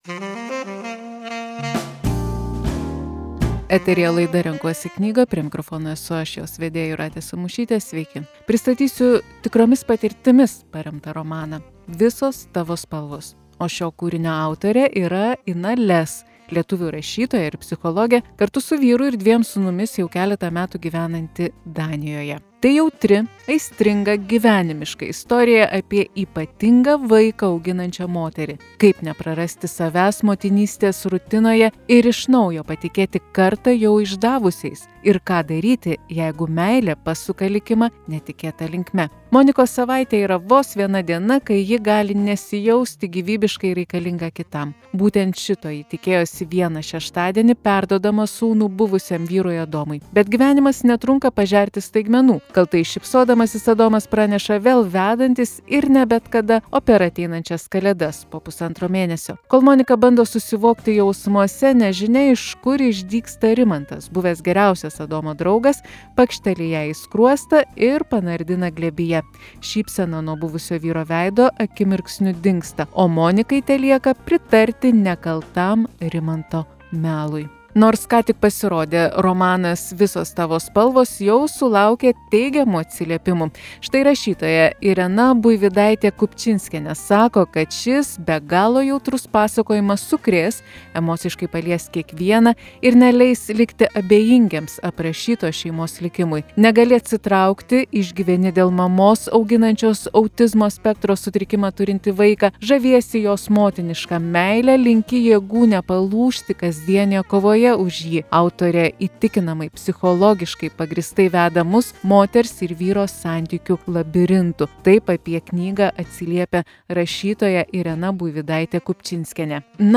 Knygos apžvalga.